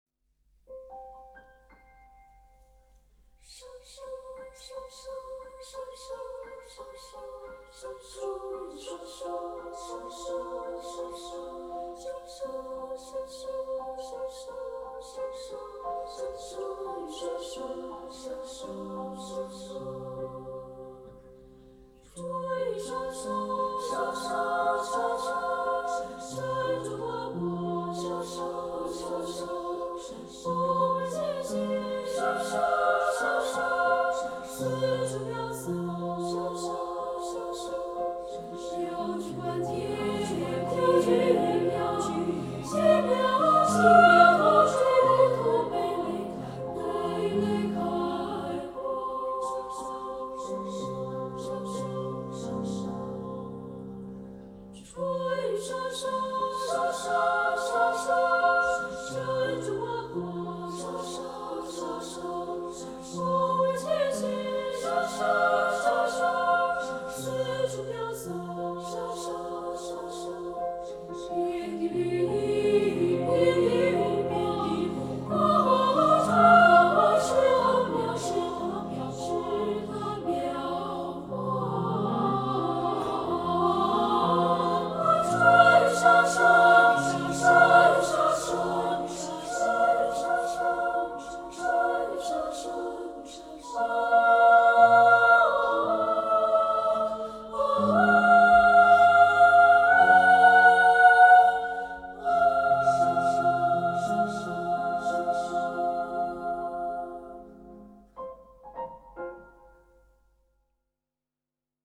童声合唱的音色明净、清澈，它表现了孩子们水晶般纯洁的心灵。